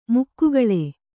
♪ mukkugaḷe